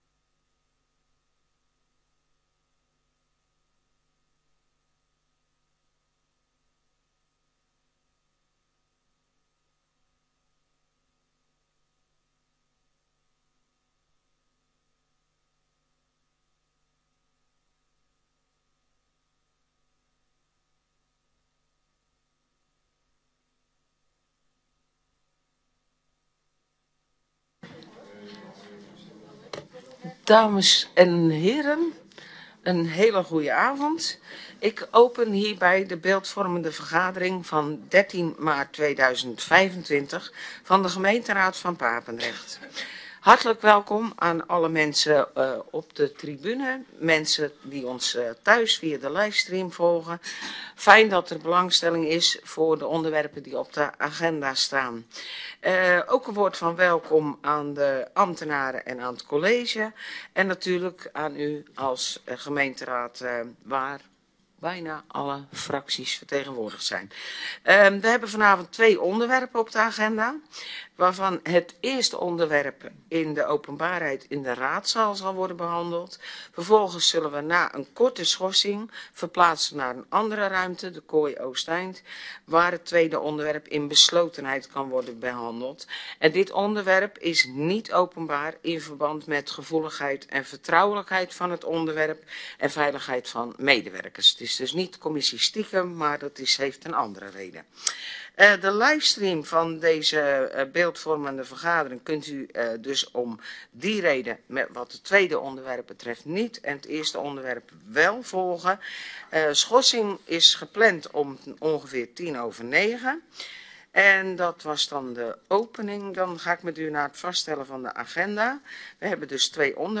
Download de volledige audio van deze vergadering
Voorzitter: Margré van Wijngaarden
Locatie: Raadzaal